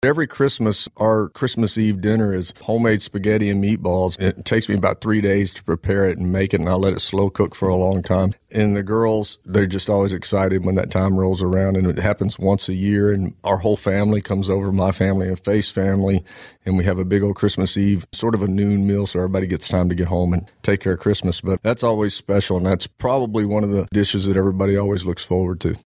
Tim tells us more: